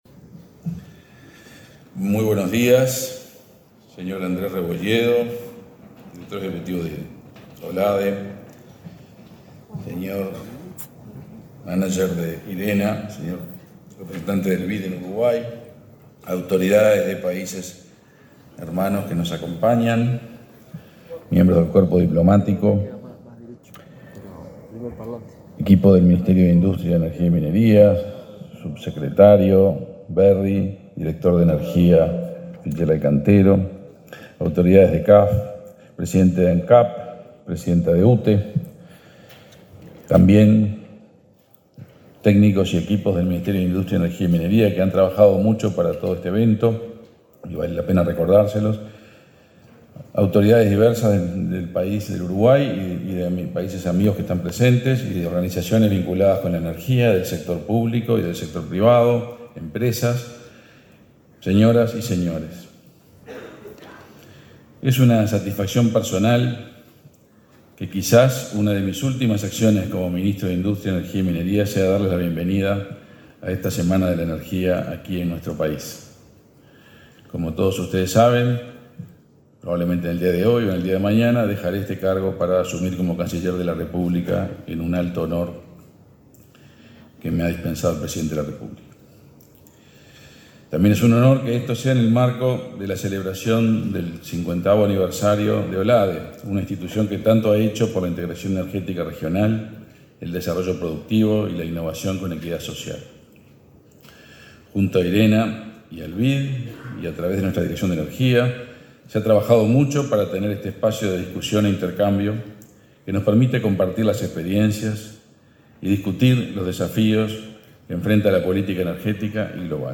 Palabra de autoridades en seminario de energía
El ministro de Industria, Omar Paganini, y la directora general de esa cartera, Elisa Facio, participaron, este lunes 6 en Montevideo, de la apertura